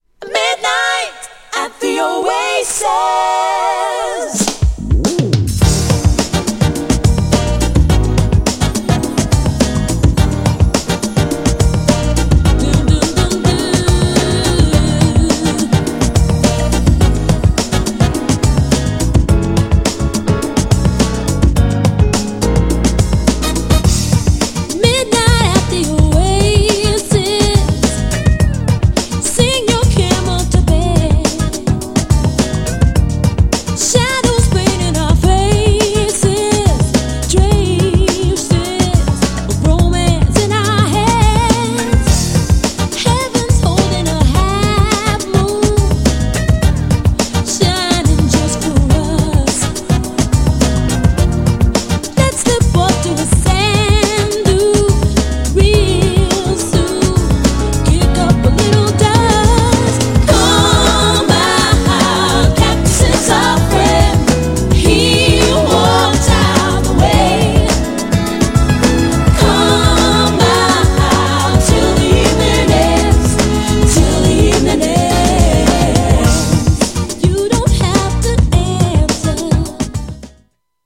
GENRE R&B
BPM 106〜110BPM